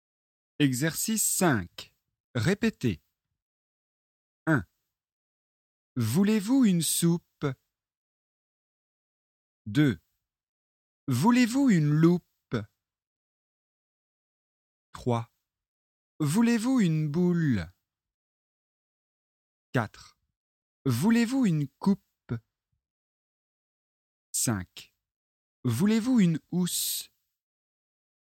Leçon de phonétique, niveau débutant (A1).
Exercice 5 : répétez.